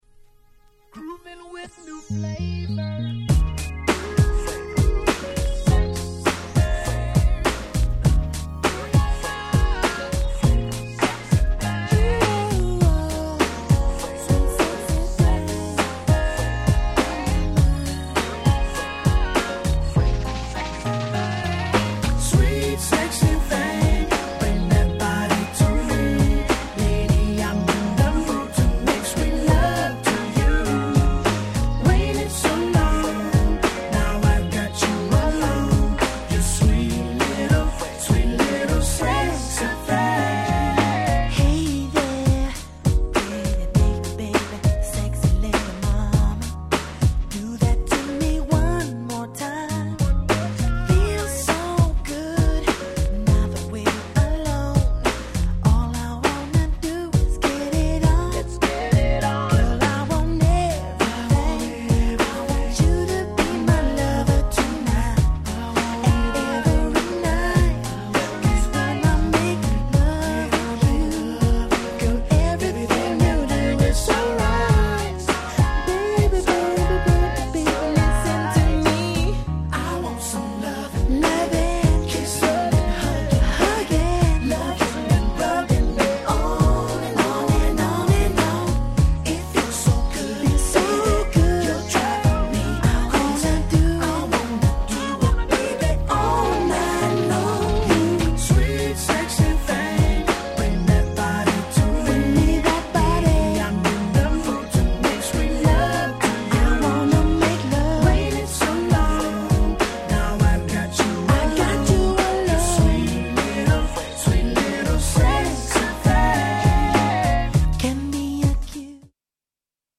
出ました、大人気超絶Sweetチューン！
ヴォコーダーが超気持ち良く、ウエッサイ系のDJにも人気がある1枚。
トークボックス Talk Box 90's